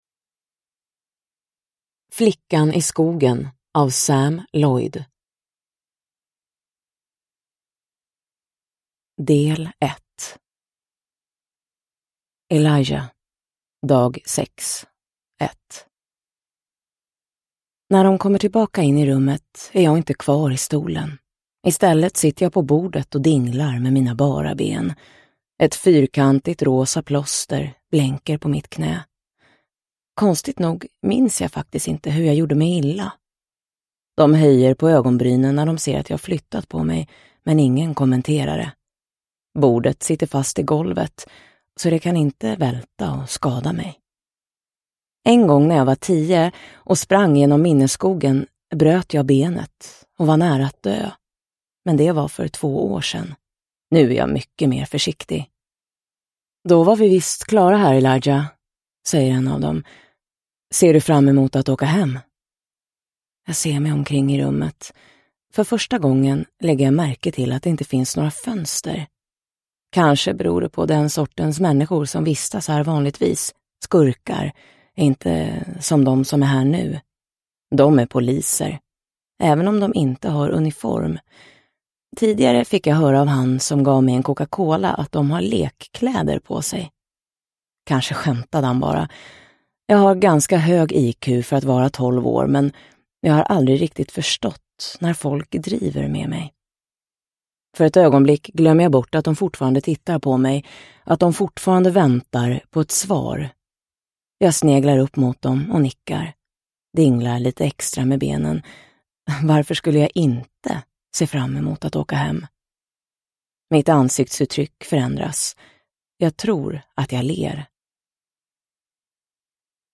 Flickan i skogen – Ljudbok – Laddas ner